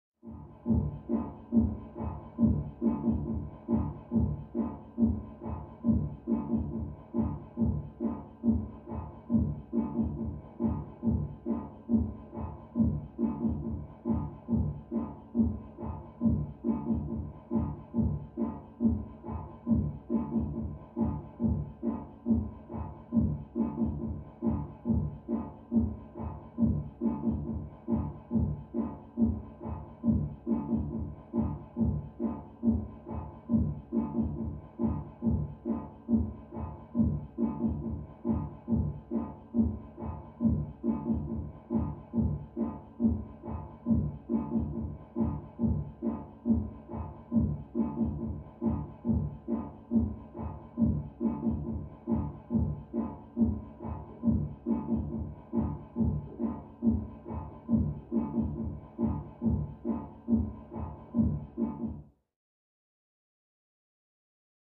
Music; Electronic Dance Beat, From Down Hallway.